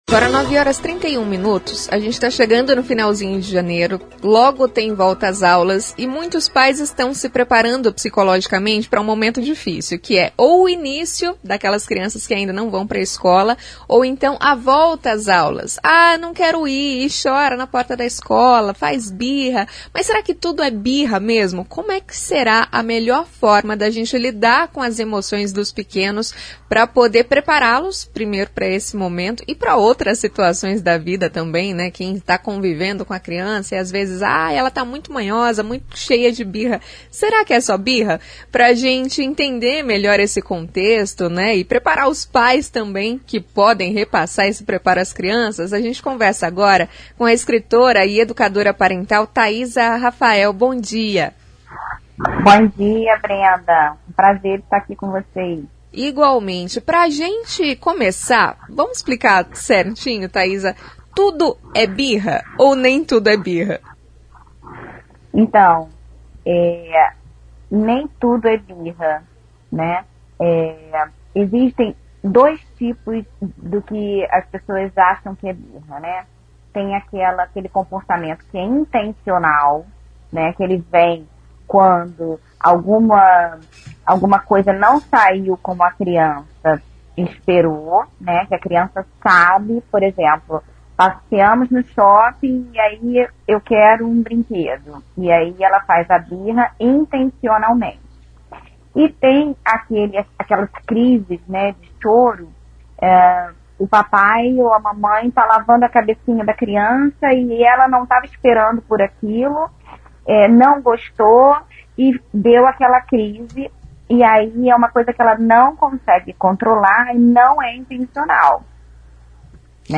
Em entrevista à CBN Maringá, a educadora ensina técnicas simples e acessíveis para lidar com os momentos de desacordo.